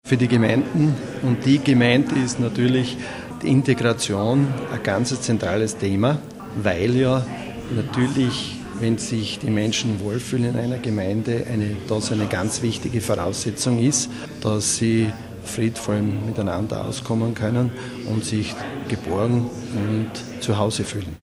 O-Ton: Partnerschaften mit dem Integrationsressort
Erwin Dirnberger, Gemeindebund